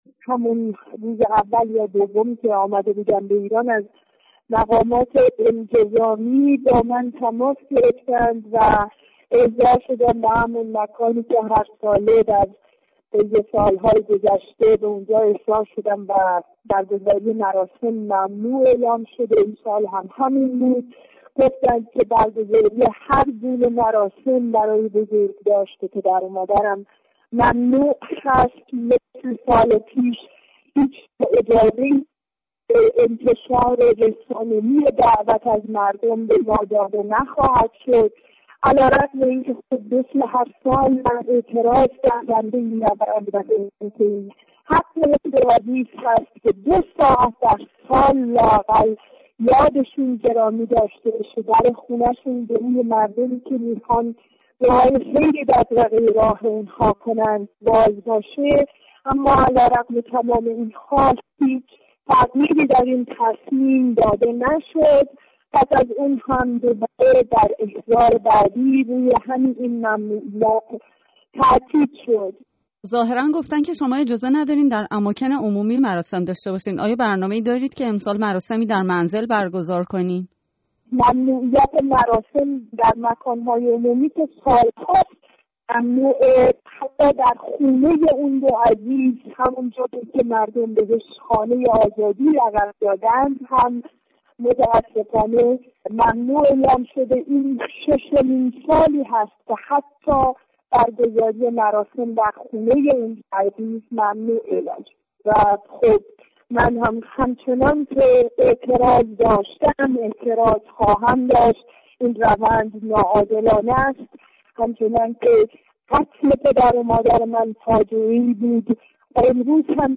گفت‌و‌گوی